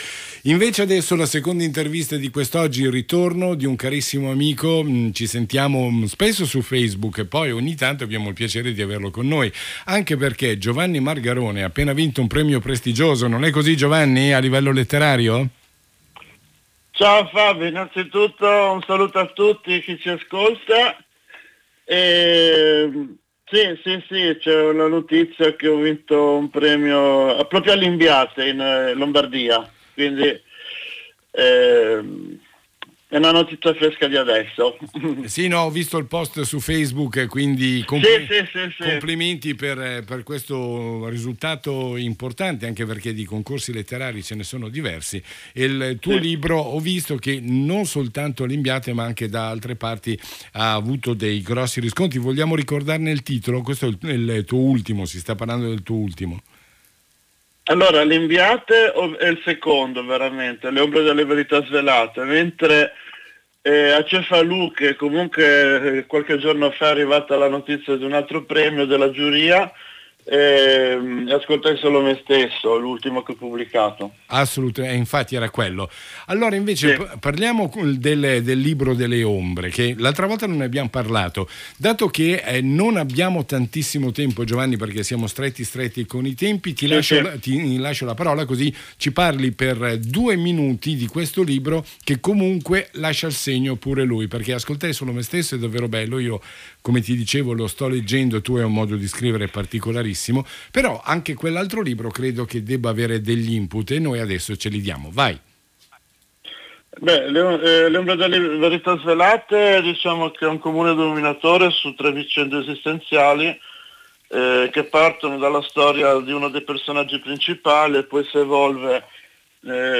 RASSEGNA STAMPA: intervista a Radio Cernusco Stereo del 7 marzo 2020